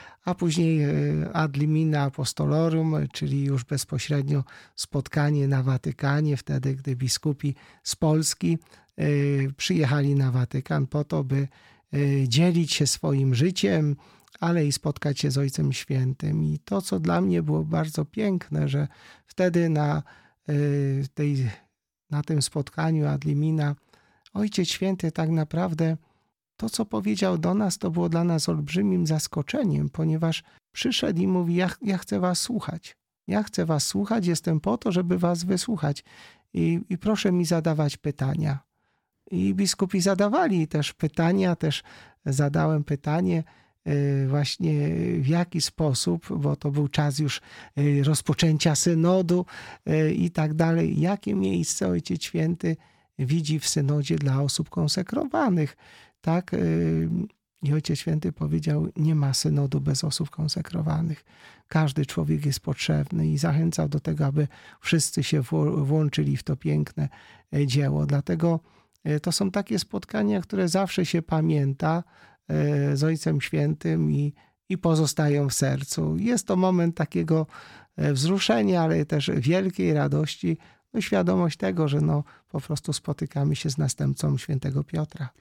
W rozmowie z Radiem Rodzina opowiedział nie tylko o wspomnieniach jakie pozostaną w nim po papieżu, ale też o przesłaniu jego pontyfikatu.